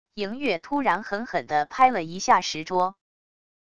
赢月突然狠狠的拍了一下石桌wav音频生成系统WAV Audio Player